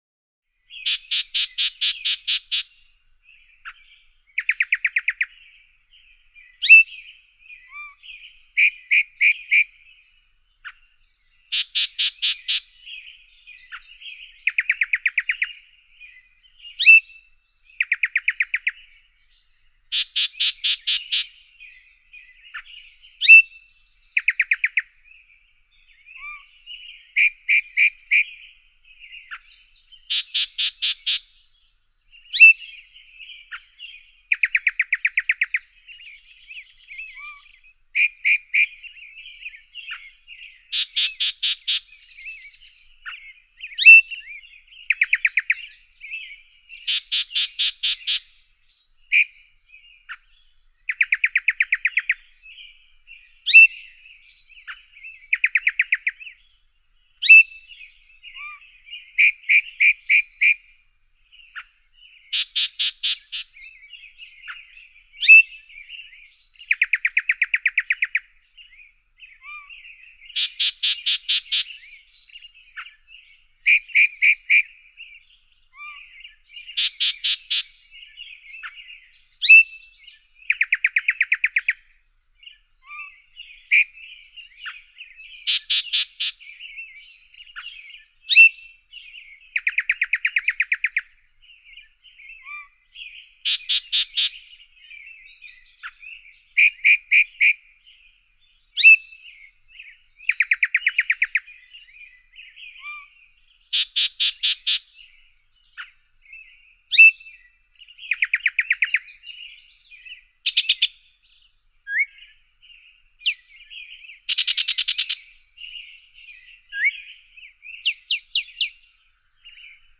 Chat, Yellow-breasted Chirps, Whistles, Caws, Tweets, Squawks, A Wide Variety Of Different Bird Calls In The Foreground And Background. Chirps, Whistles, Caws, Tweets And Squawks Are All Heard.